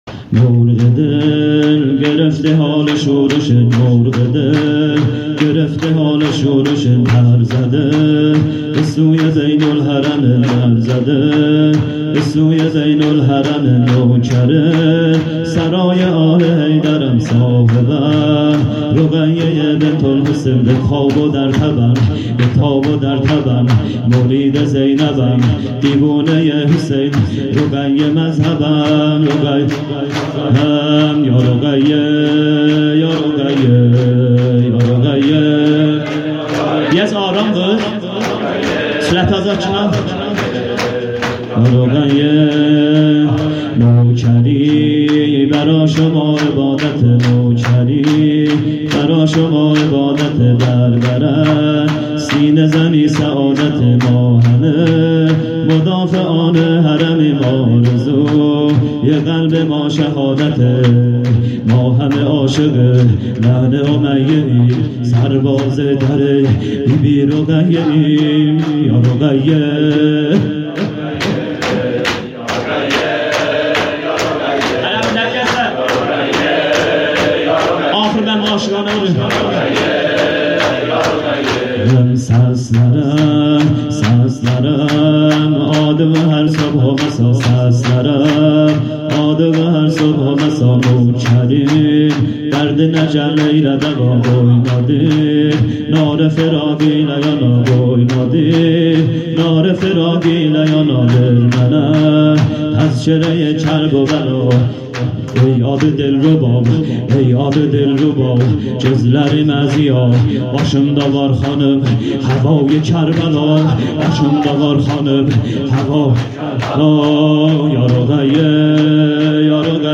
شور و روضه پایانی